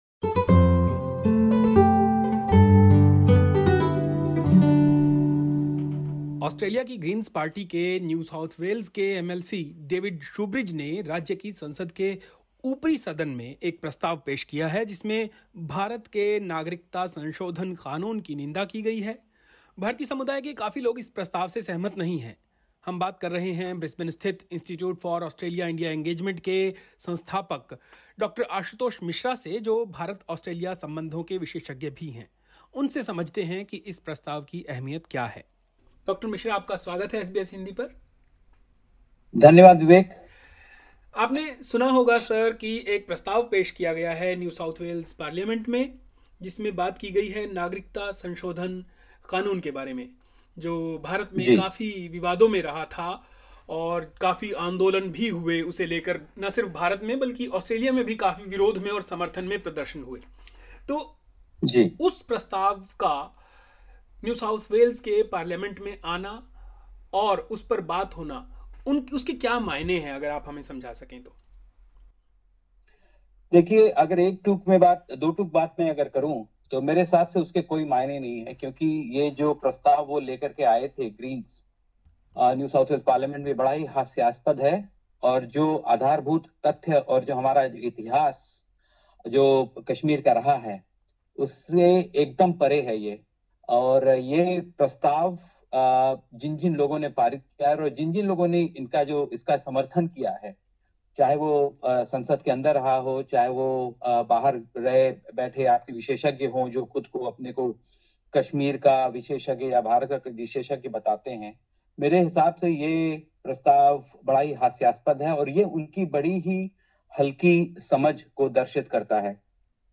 पूरी बातचीत यहां सुनेंः LISTEN TO 'CAA पर ग्रीन्स पार्टी का प्रस्ताव हास्यास्पद है' SBS Hindi 08:22 Hindi वह कहते हैं कि इस प्रस्ताव के कोई मायने नहीं हैं.